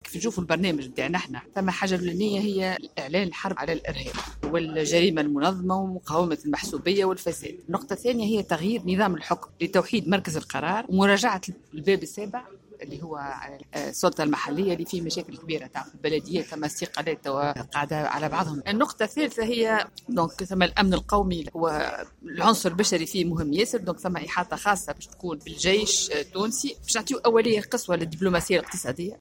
قالت سلمى اللومي المترشحة للانتخابات الرئاسية السابقة لأوانها خلال حملتها في الكاف أن برنامجها الإنتخابي يرتكز على مكافحة الإرهاب ومقاومة الجريمة والمحسوبية والفساد.